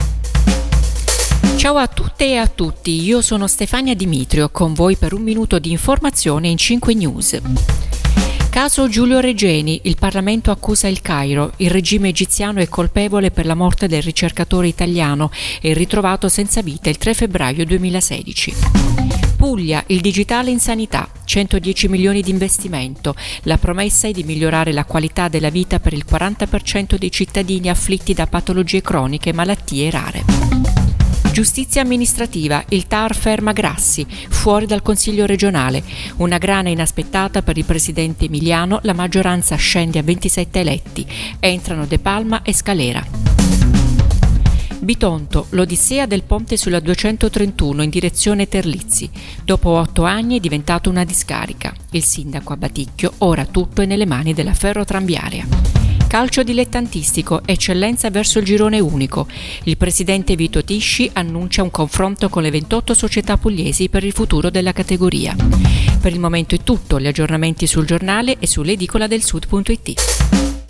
Giornale radio alle ore 7